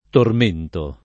tormento [ torm % nto ] s. m.